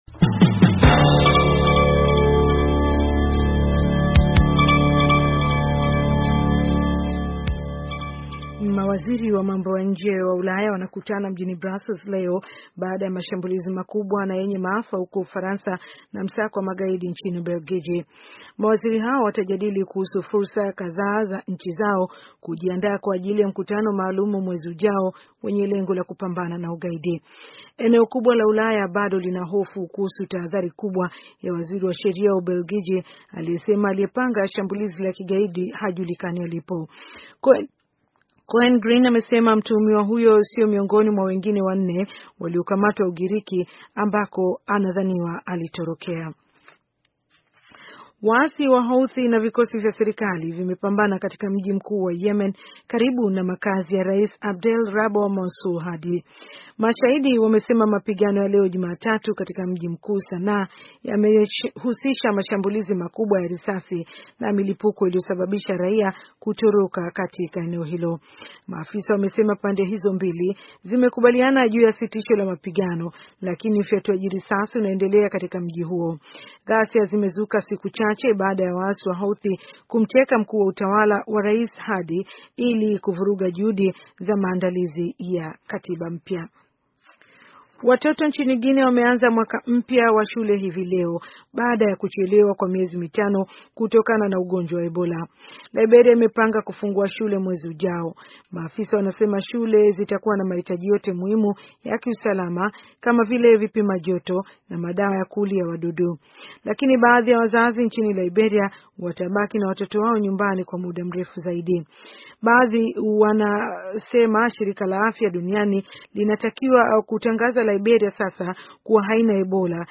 Taarifa ya habari - 5:34